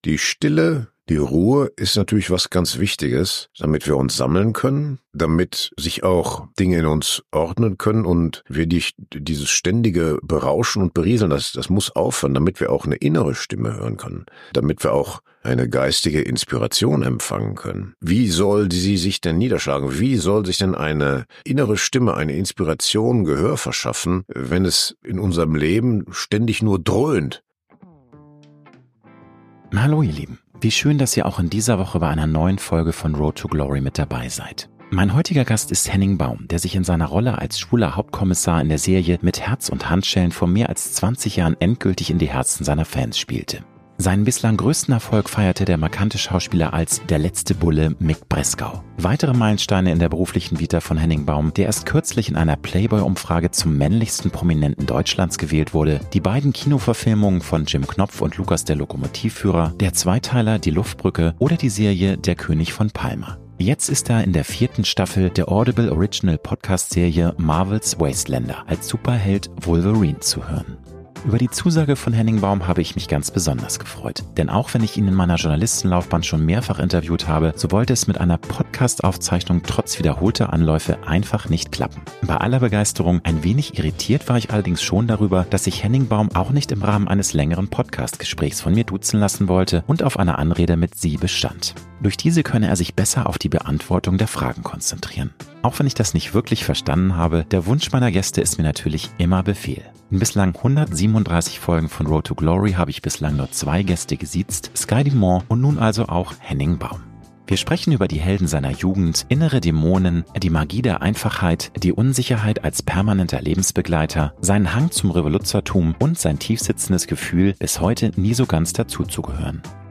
~ Road to Glory - Promi-Talk